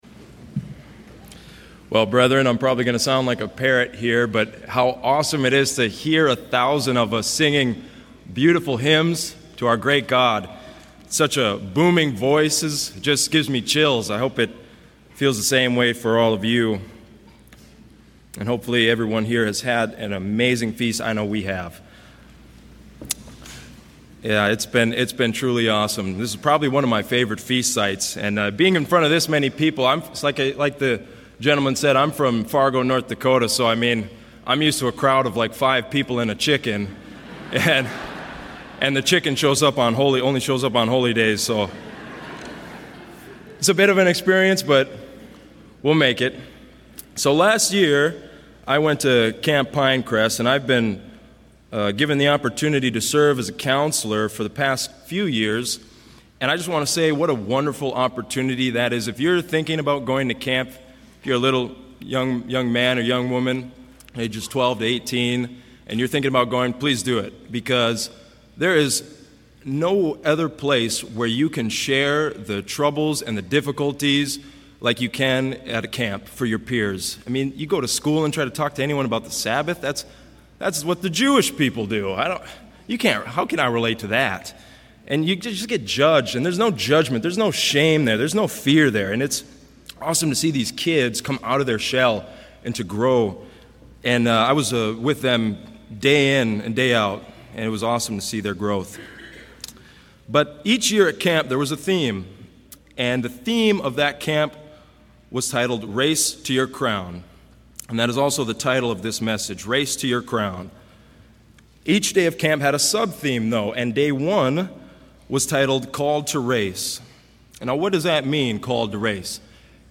This message was given during the 2024 Feast of Tabernacles in Panama City Beach, Florida.